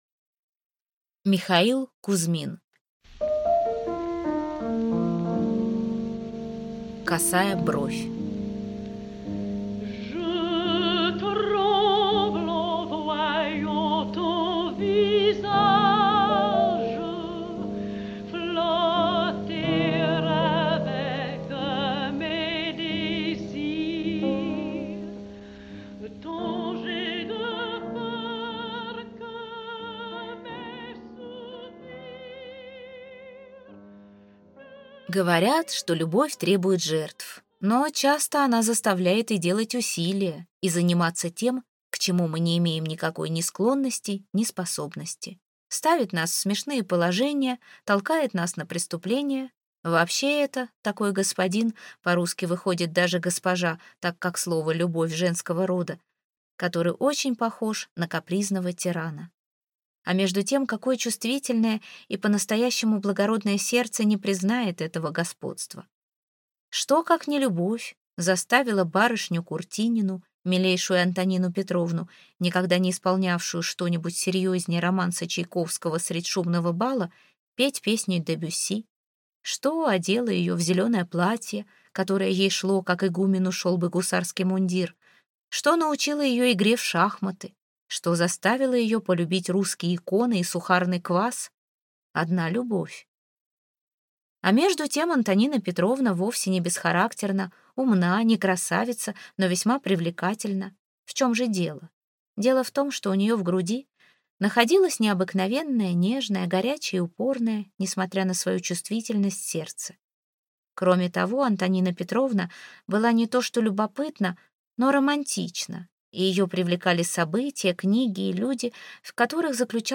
Аудиокнига Косая бровь | Библиотека аудиокниг